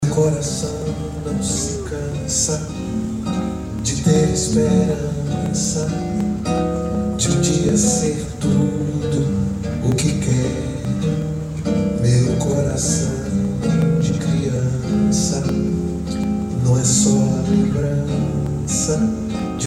Emotivo concierto